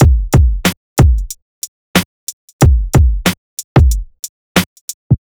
HP092BEAT1-R.wav